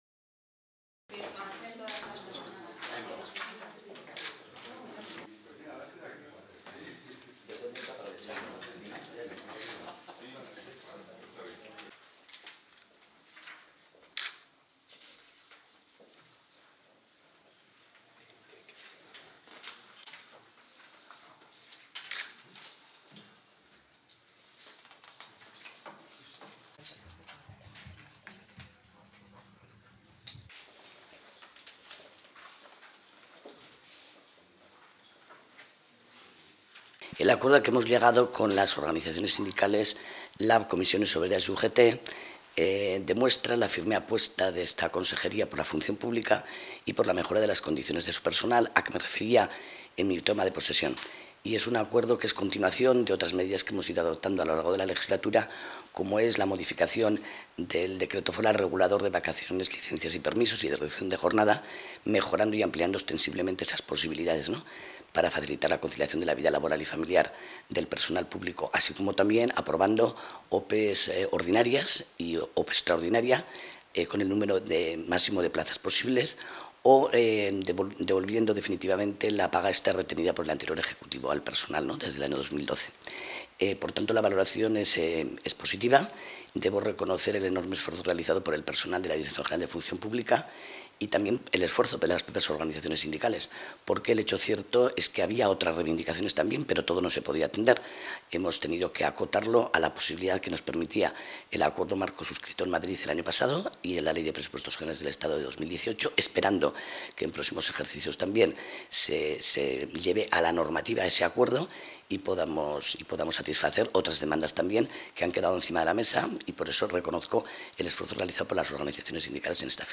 Declaraciones consejera Beaumont.